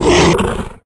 attack_hit_2.ogg